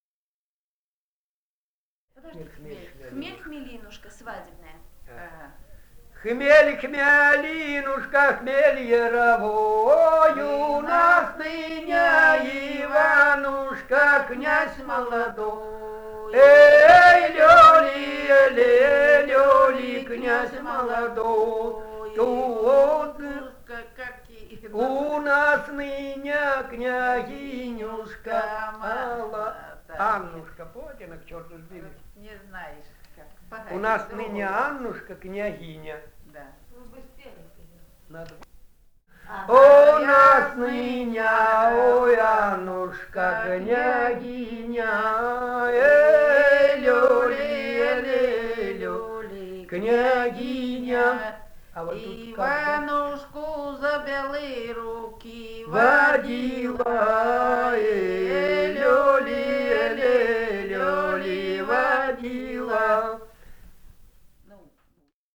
полевые материалы
Алтайский край, с. Михайловка Усть-Калманского района, 1967 г. И1001-05